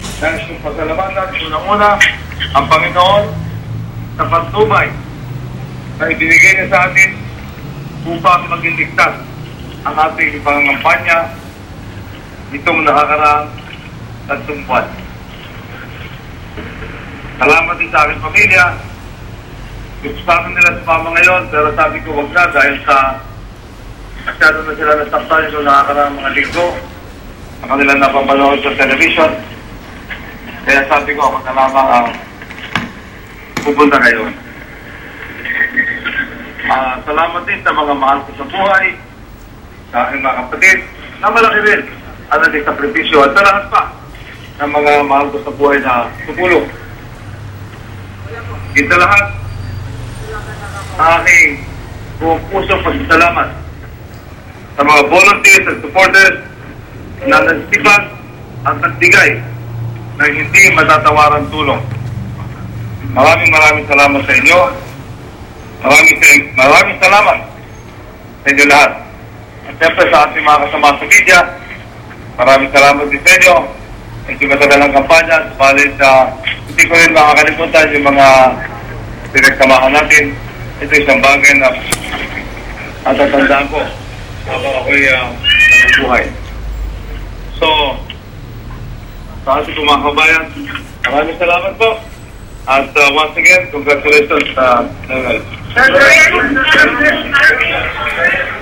Villar called a press conference at around 11 a.m. in Mandaluyong, while results of the Comelec count showed him trailing at third place with some 4 million votes, behind Aquino who was leading with more than 12 million votes, and Estrada at around 8 million.
Villar’s concession speech.